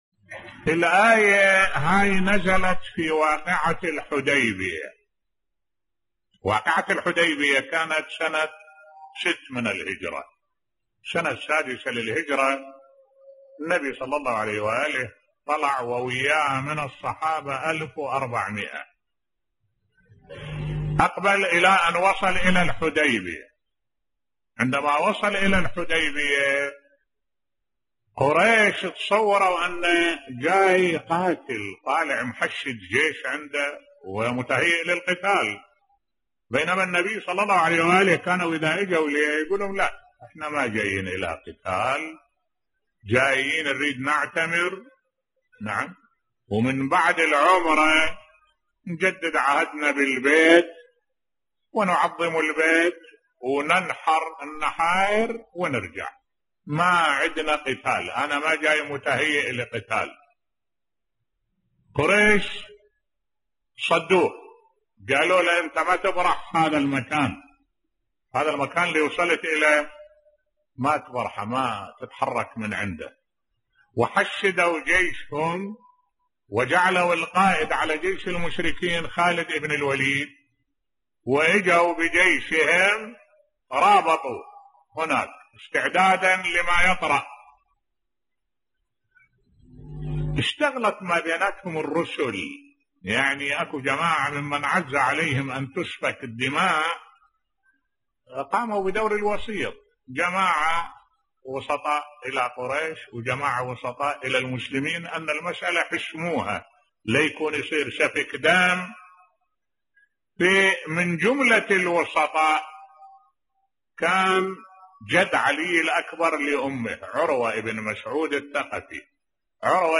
ملف صوتی سبب نزول آية لقد رضي الله على المؤمنين اذ يبايعونك تحت الشجرة بصوت الشيخ الدكتور أحمد الوائلي